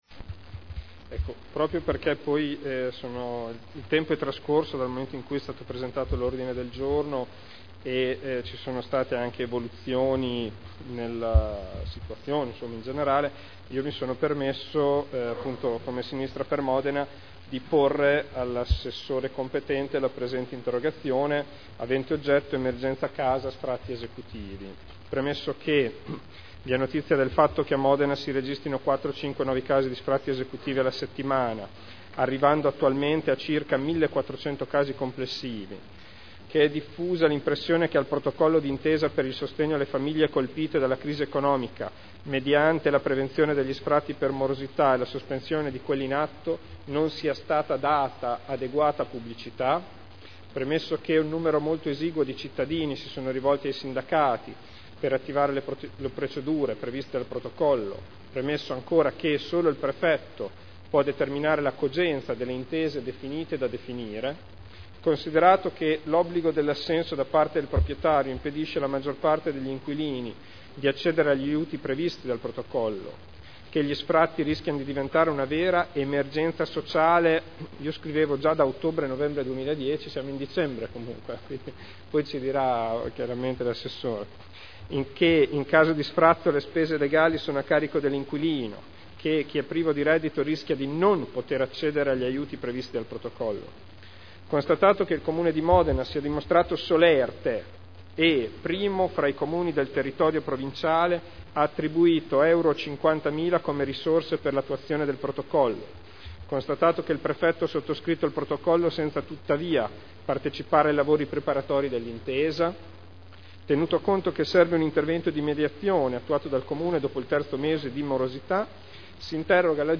Federico Ricci — Sito Audio Consiglio Comunale